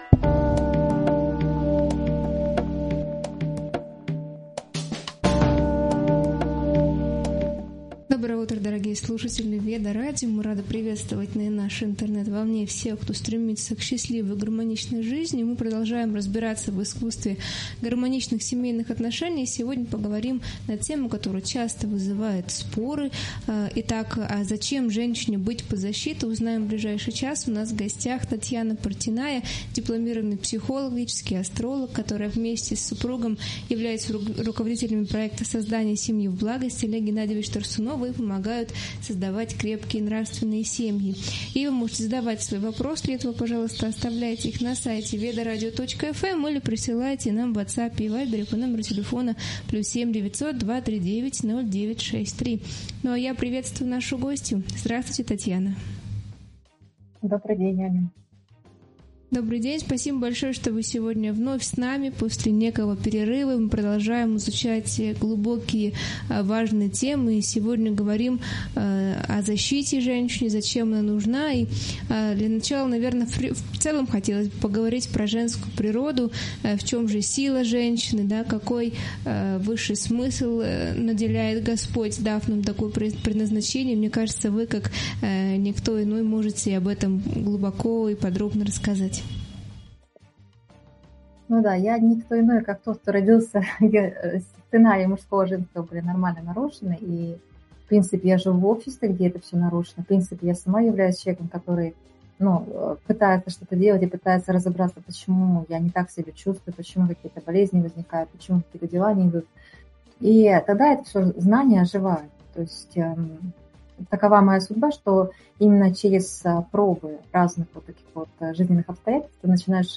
Эфир посвящён роли и миссии женщины в современном обществе, её внутренней силе и ответственности в семье. Обсуждаются влияние стереотипов, гармония отношений с мужчинами, баланс между карьерой и личной жизнью, духовное развитие через обязанности и самопознание. Особое внимание уделено созданию благоприятной семейной среды, эмоциональному здоровью и защите женщин.